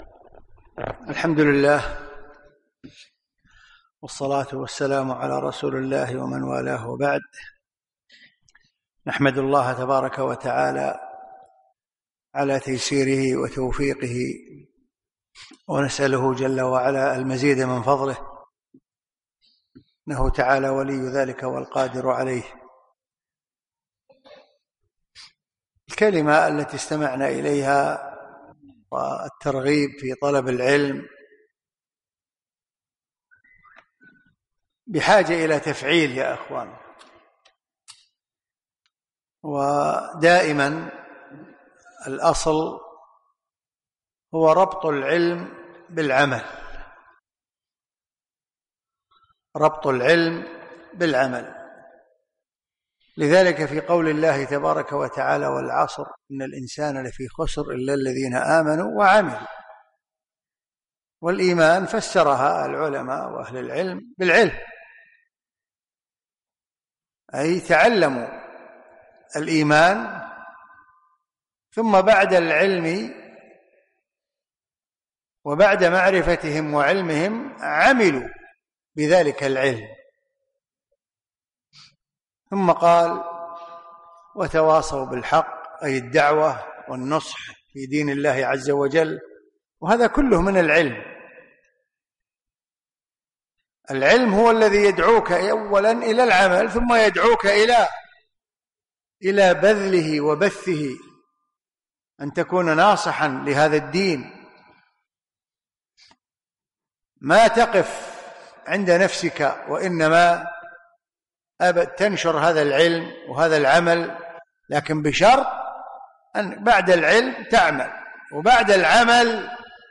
كلمة في إفتتاحية دورة الخليفة الراشد الخامسة عشر